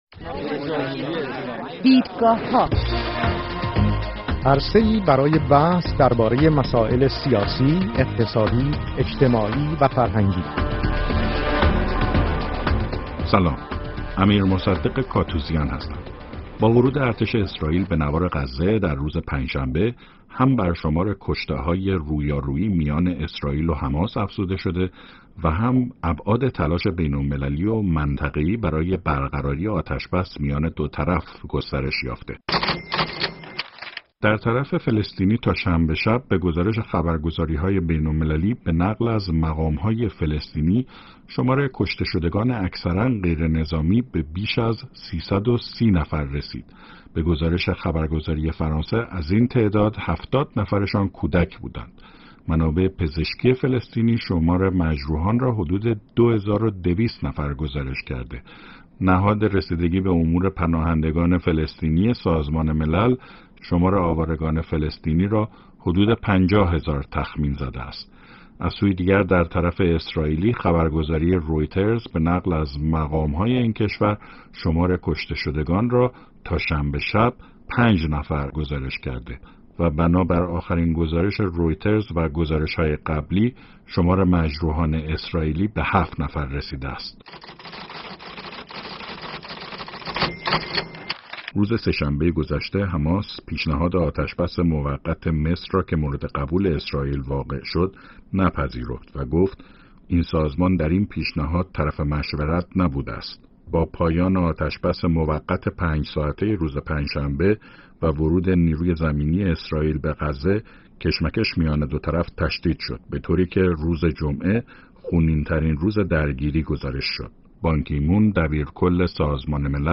با ورود ارتش اسرائیل به نوار غزه هم بر شمار کشته‌های رویارویی میان اسرائیل و حماس افزوده شده و هم ابعاد تلاش بین‌المللی و منطقه‌ای برای برقراری آتش بس میان دو طرف گسترش یافته است. سه میهمان این هفته برنامه «دیدگاه‌ها» ابعاد مختلف رویارویی اسرائیل با حماس را بررسی می‌کنند.